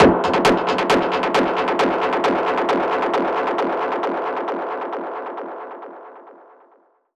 Index of /musicradar/dub-percussion-samples/134bpm
DPFX_PercHit_D_134-04.wav